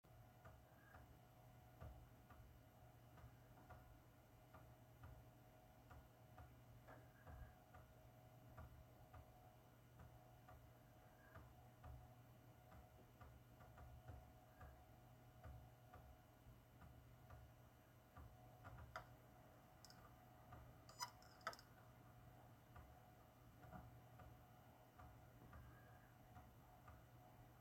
Beim kopieren von 1TB Daten von einer älteren Samsung HDD (auch per SATA Kabel am Board) auf die Toshiba fallen mir neben einem kontinuierlichen, regelmäßigen, dumpfen "Wummern" (denke das ist normal), ab und zu kurze, unregelmäßige "Klacker"- bzw. "Kriesel"-Geräusche auf (ab Sekunde 20 im beigefügten Audio-File). Momentan hab ich das Gehäuse offen, so dass man es gut hören kann.